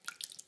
SplashCamera_02.ogg